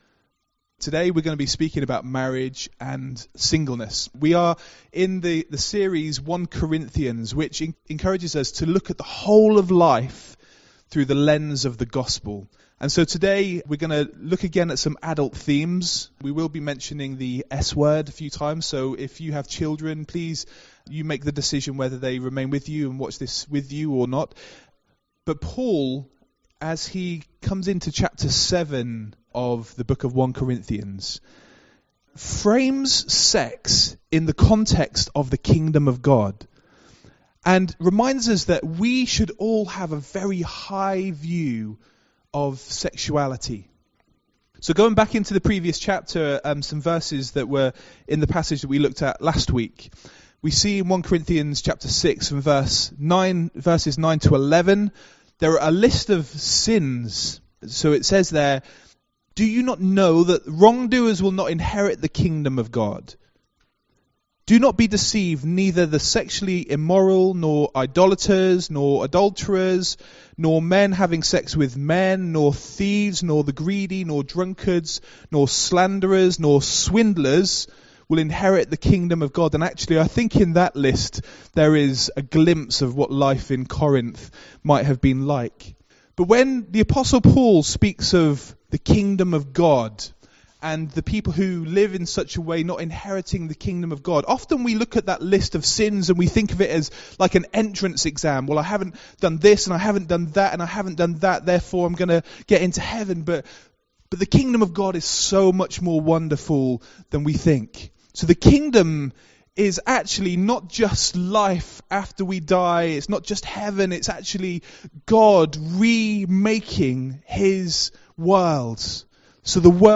MP3 SUBSCRIBE on iTunes(Podcast) Notes Sermons in this Series Our relationships within the church are important. We learn from 1 Corinthians 7 that being married or single are just as valid and that there is no right or wrong.